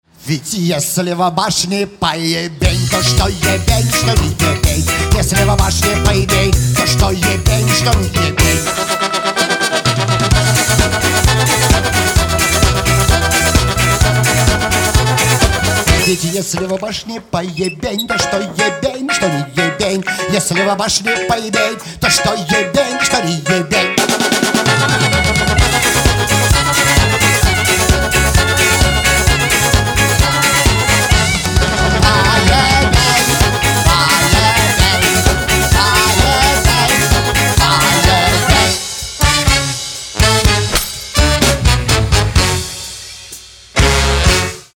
• Качество: 320, Stereo
громкие
веселые
инструментальные